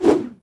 footswing2.ogg